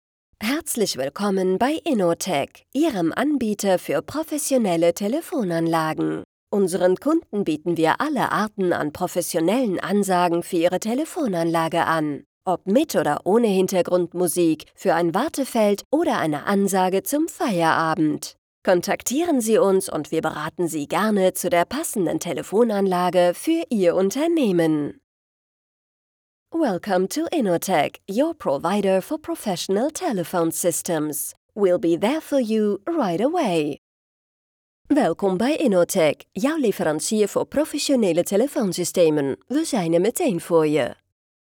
Ansagen
Sprecher weiblich 3